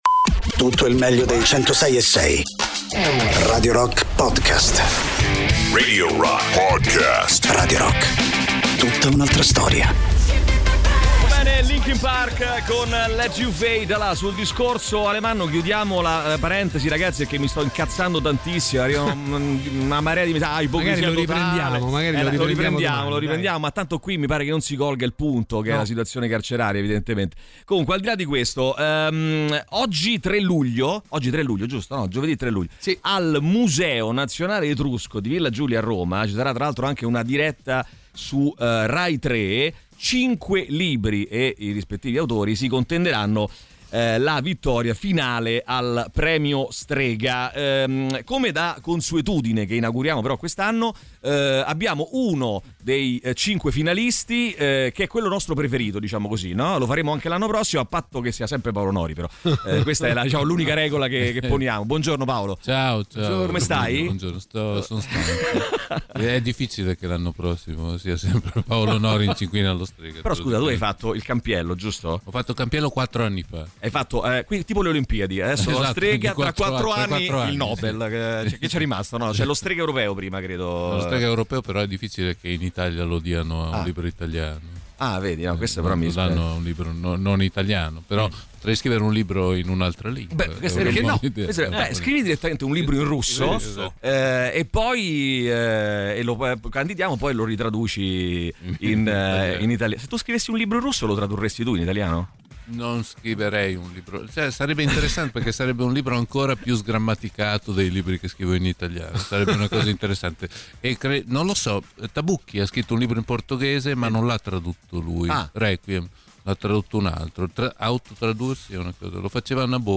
Interviste: Paolo Nori (03-07-25)